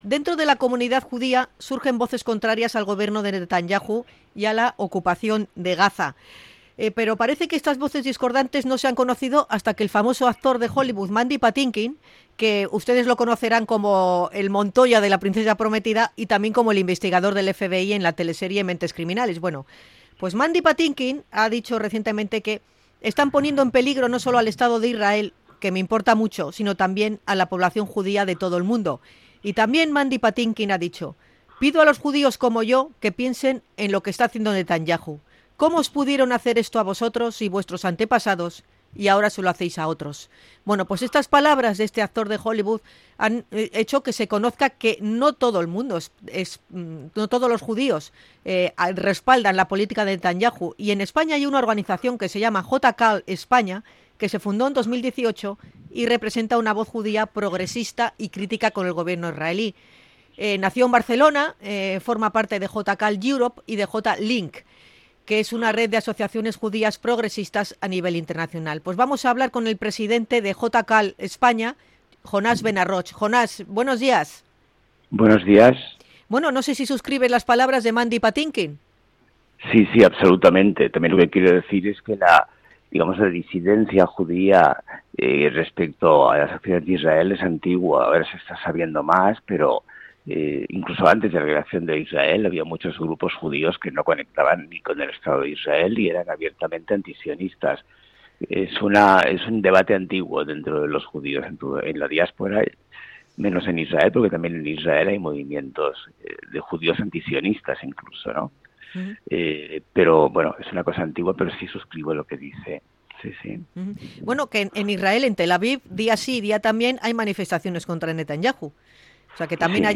INT.-JUDIOS-CONTRA-NETANYAHU.mp3